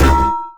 twang.wav